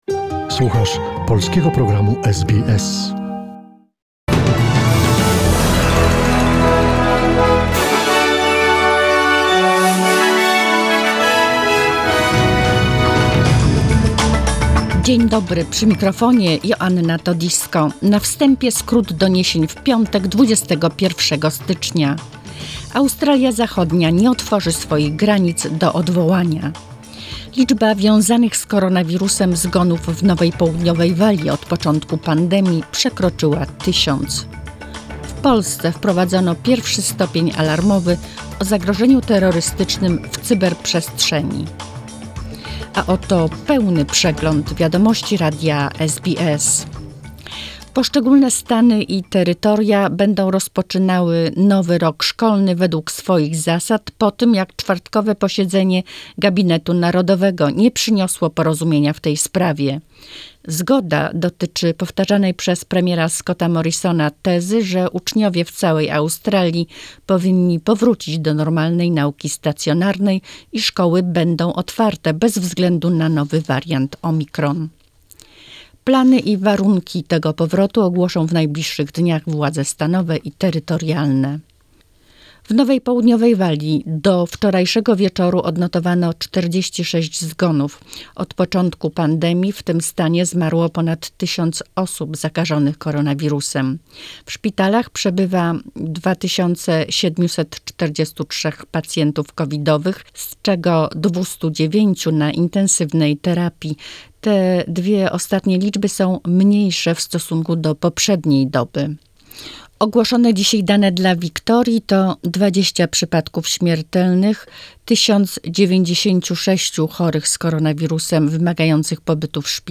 SBS News in Polish, 21 January 2022